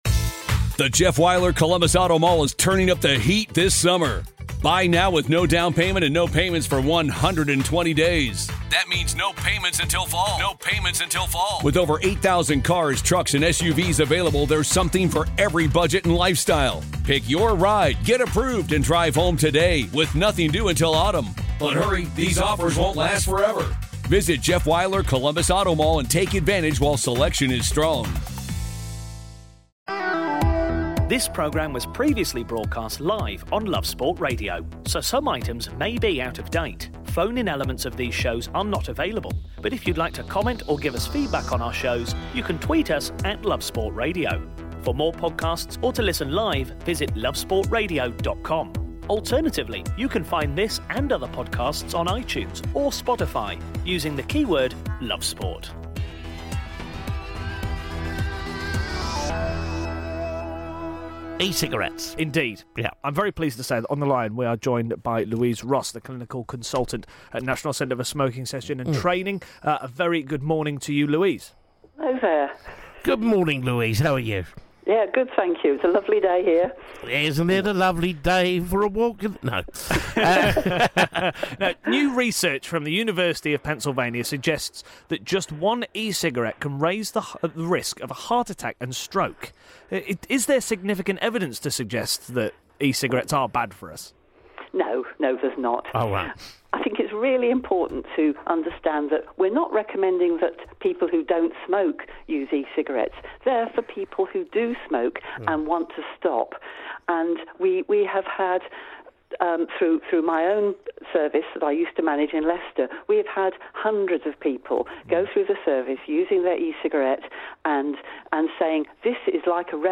Plus an interview with former Newcastle and Northern Ireland player Tommy Cassidy